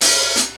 Closed Hats
07_Perc_06_SP.wav